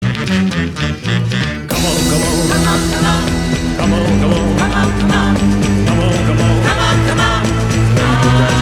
• Качество: 192, Stereo
ритмичные
веселые
инструментальные
мужской и женский вокал
60-е